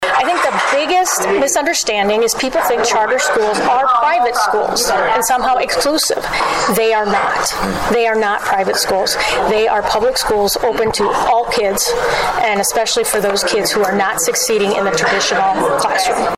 District 18 Legislative Cracker Barrel Held in Yankton